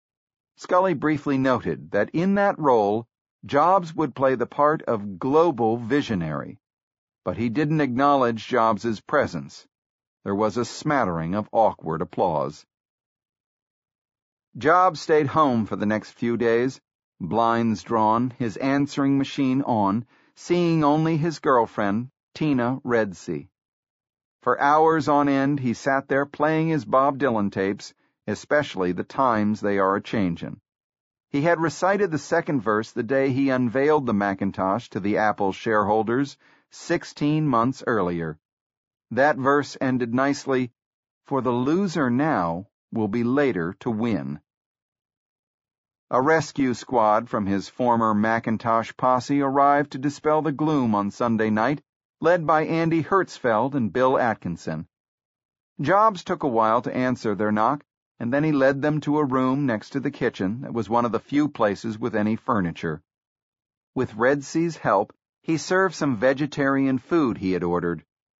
在线英语听力室乔布斯传 第205期:像一块滚石(2)的听力文件下载,《乔布斯传》双语有声读物栏目，通过英语音频MP3和中英双语字幕，来帮助英语学习者提高英语听说能力。
本栏目纯正的英语发音，以及完整的传记内容，详细描述了乔布斯的一生，是学习英语的必备材料。